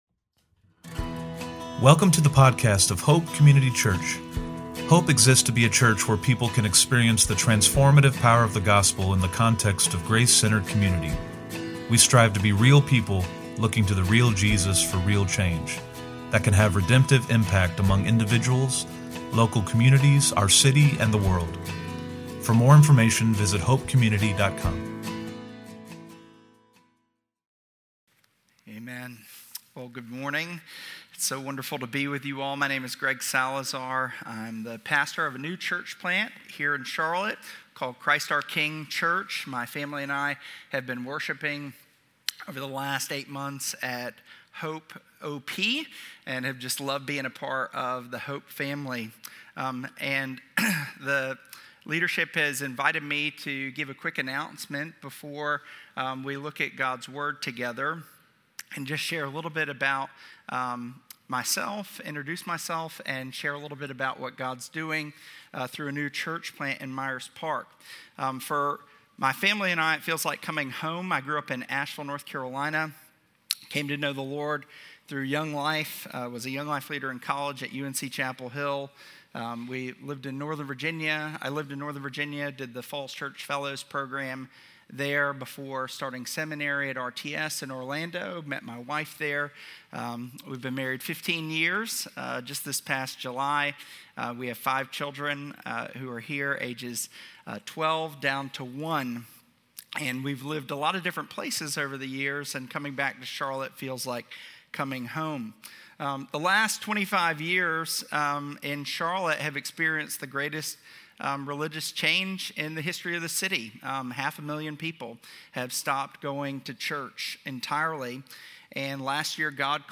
SE-Sermon-8.31.25.mp3